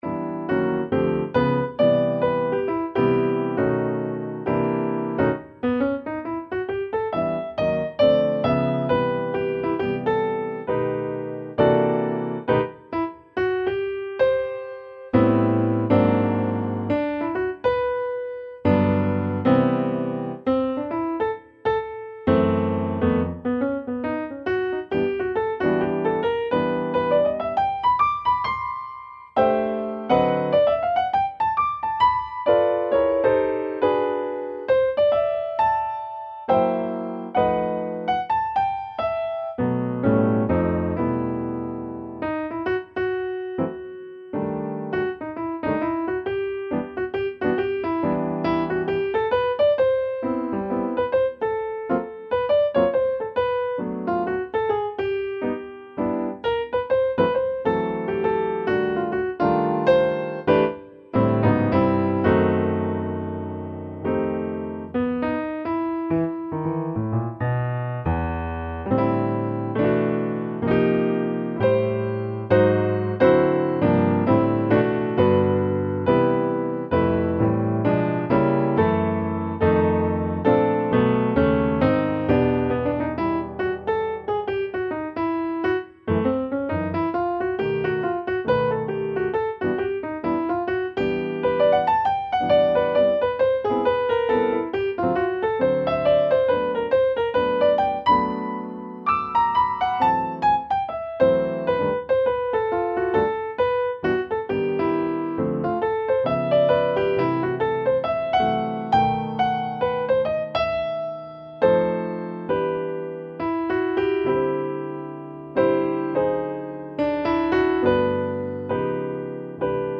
Interesting voicings.